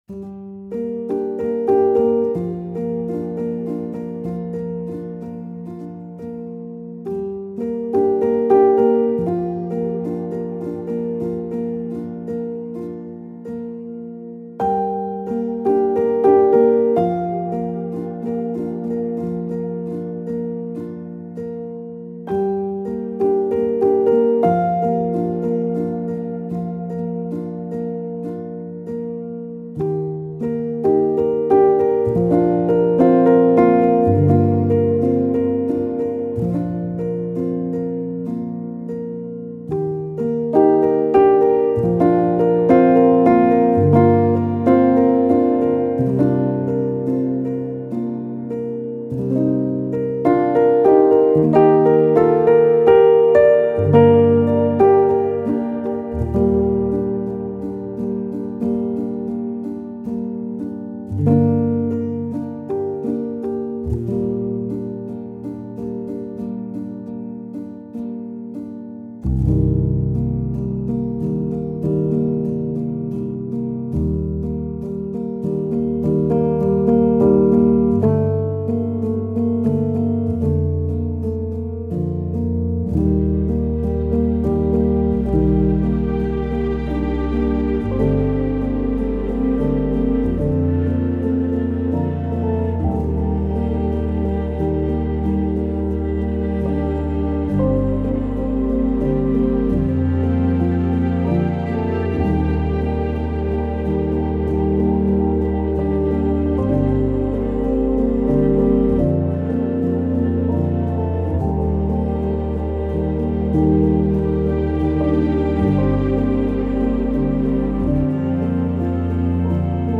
Remote solitary melodies, skim vast emotional landscapes.